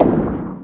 [fx] gun stomp.wav